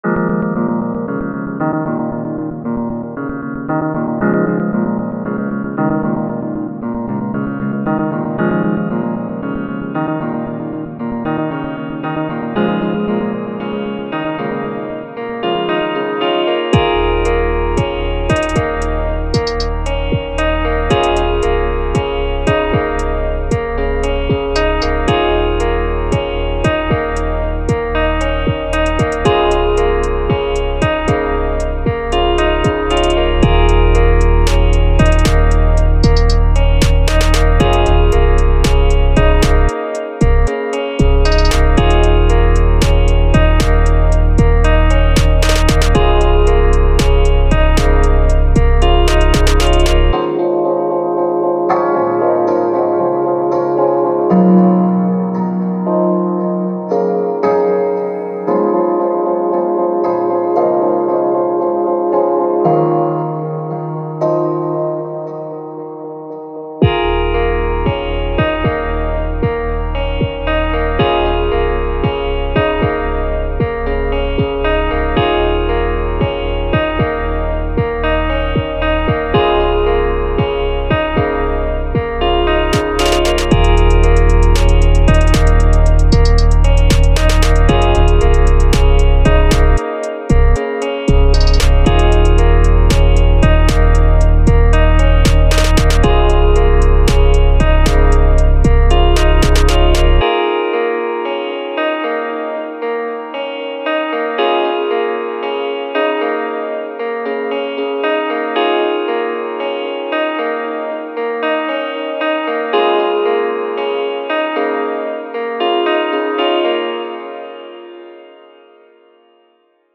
lil peep type beat сведение и мастеринг хелп
Тут назрел мой первый опыт в написании полноценной гитарной мелодии, но в очередной раз всплыла проблема с обработкой ударок - вроде всё делал по видеоурокам (808 в тон, софтклиппер на кик и т.д.), но всё равно звучат очень сухо и пластмассово что ли.